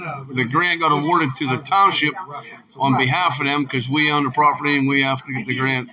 Supervisor Matt Housholder explained why the Township will be the custodian of the money.